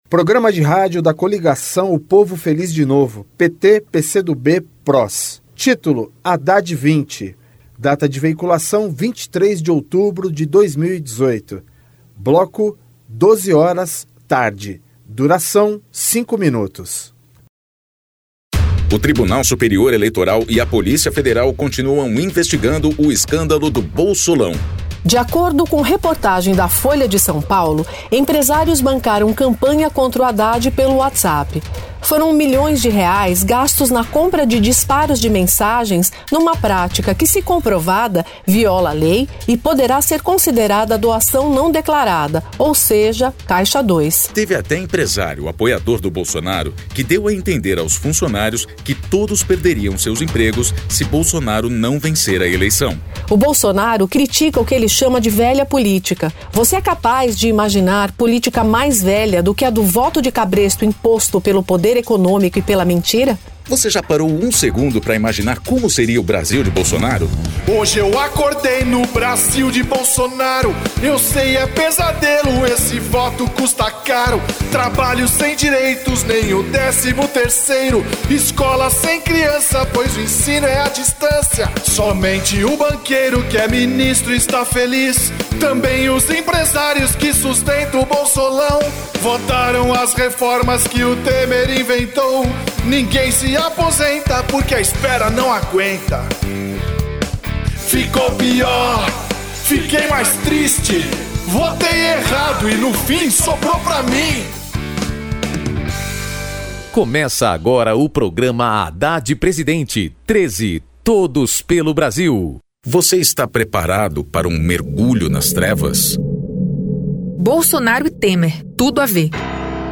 Gênero documentaldocumento sonoro
Descrição Programa de rádio da campanha de 2018 (edição 50), 2º Turno, 23/10/2018, bloco 12hrs.